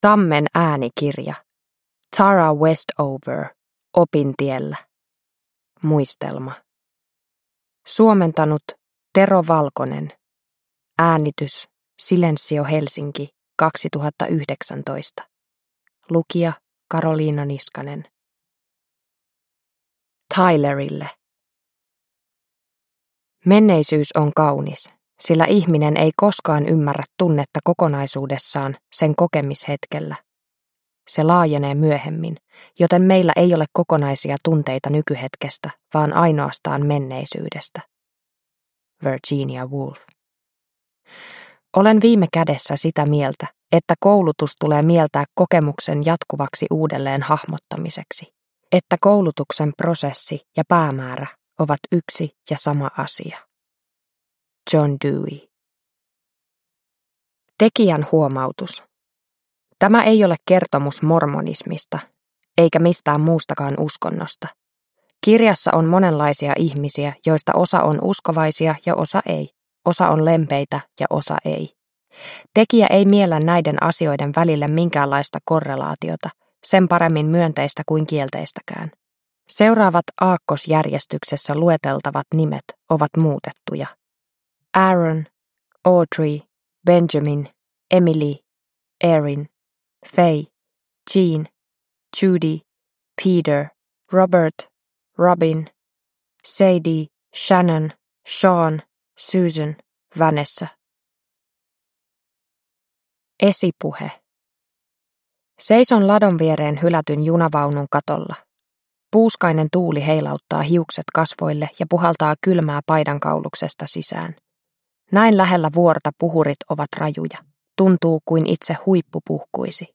Opintiellä – Ljudbok – Laddas ner